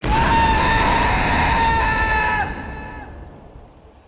Death Scream